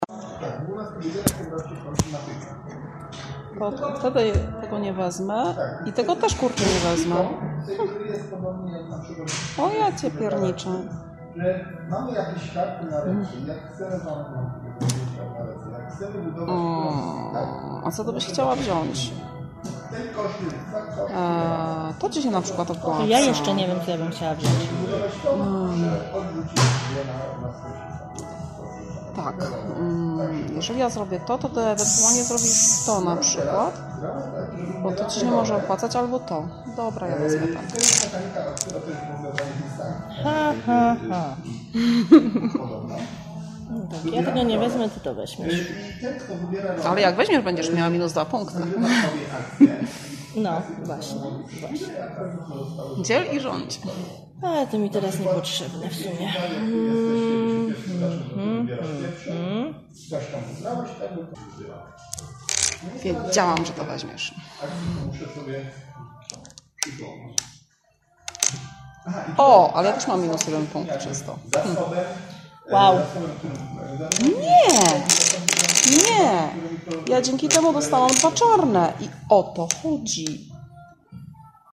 Poniżej znajdziecie fragment… nie, nie rozgrywki, ale fragment TŁUMACZENIA REGUŁ pewnej gry.
Musicie odsłuchać dokładnie tego samego fragmentu, ale tym razem, wytężając słuch, aby dosłyszeć to, co dzieje się na drugim planie! :)